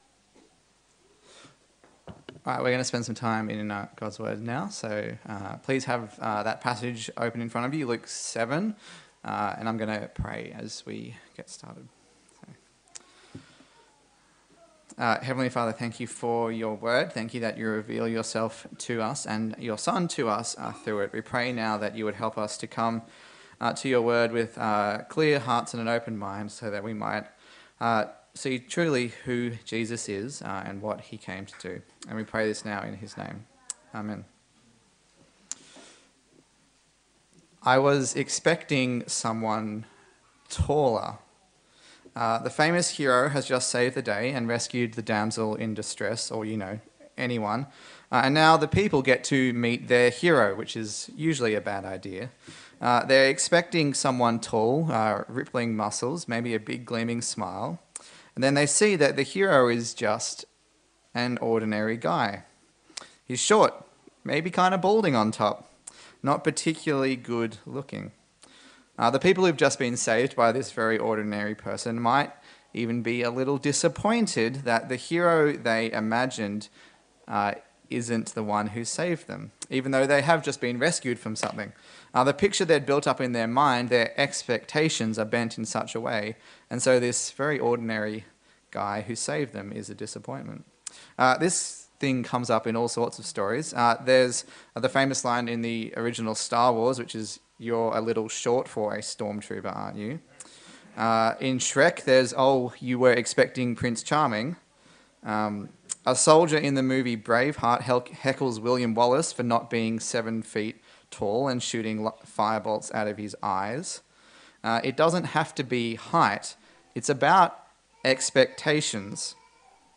Note: This recording at times jumps and makes a small amount of it hard to follow.
Luke Passage: Luke 7:18-35 Service Type: Sunday Service